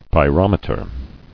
[py·rom·e·ter]